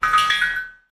Sounds I made for crystal enemies in my game Alchemist. 3 "idle" sounds and a "death" sound. Edited in Audacity from the sounds of a water harp (some fading, tempo adjustments, "death" sound is made using sliding stretch).